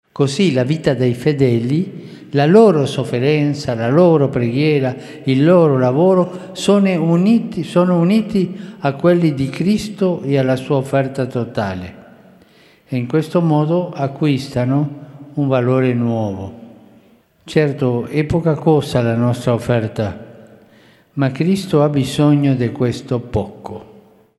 Papież Franciszek podczas środowej audiencji ogólnej wskazał na znaczenie duchowości daru z siebie.